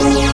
SYN DANCE0AL.wav